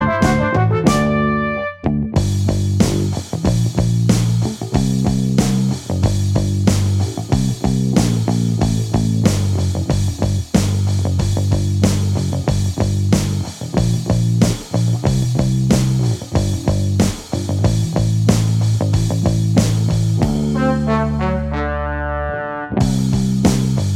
Minus Guitars Pop (1960s) 4:46 Buy £1.50